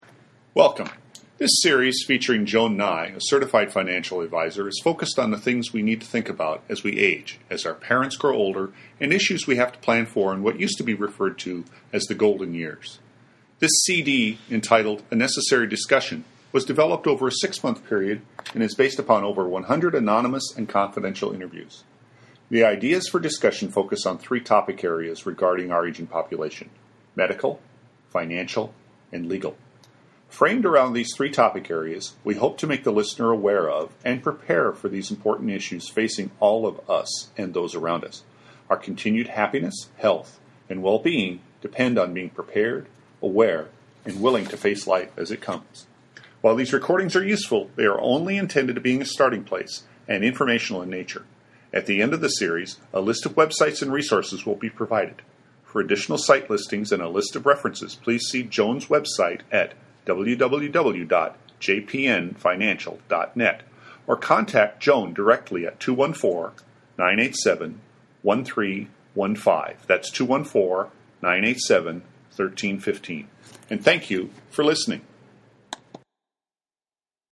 In an interview format, the audio segments cover, in a total of less than 30 minutes, what to do for your aging parent or relative with regard to their financial, medical and legal concerns.